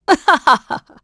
Isolet-Vox_Happy3.wav